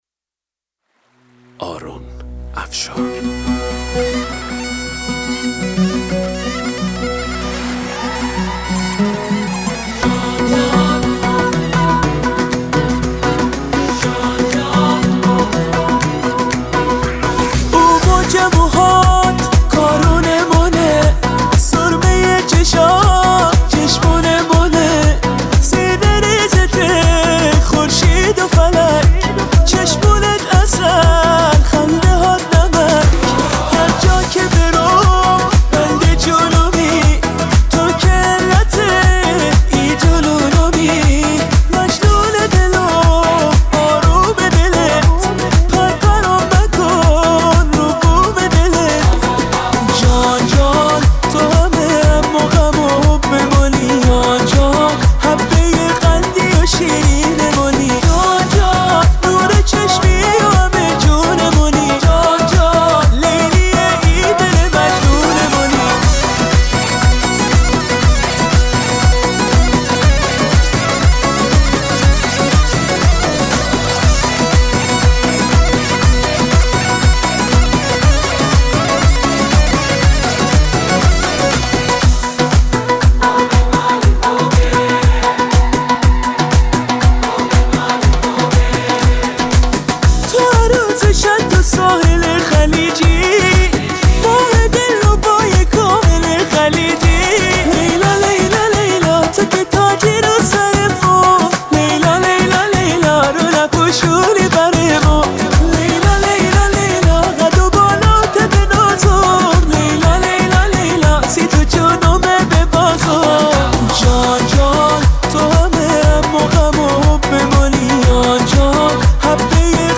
شاد